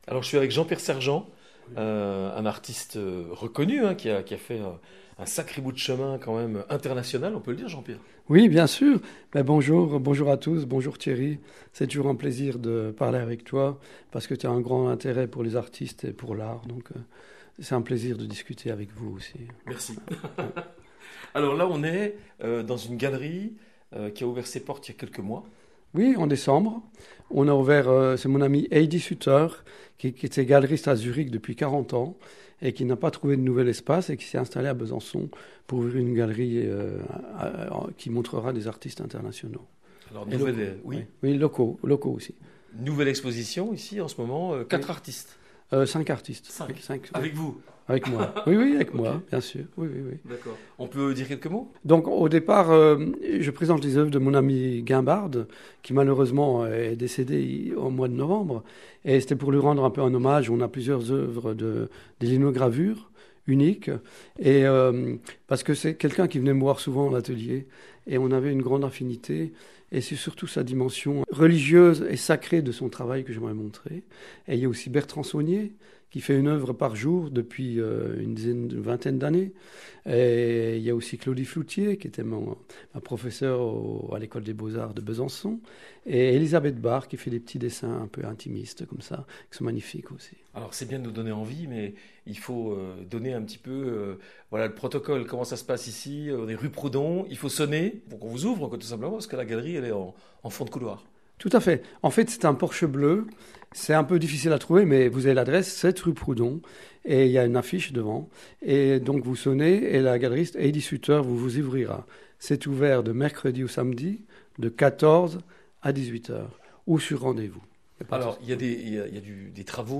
New interview